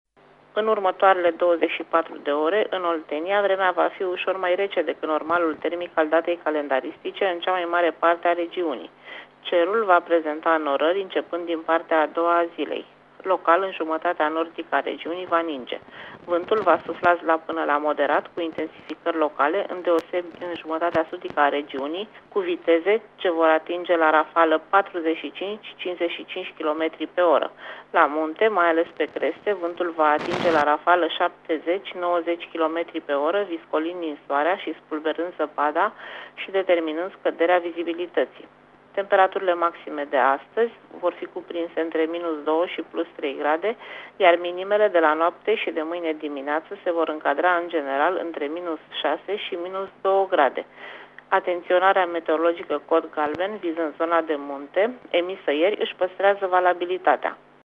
Prognoza meteo 15 ianuarie (audio)